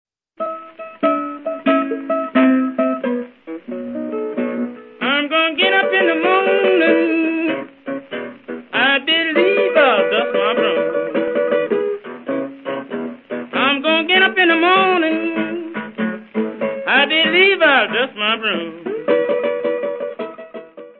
: stereo; 12 cm
Área:  Jazz / Blues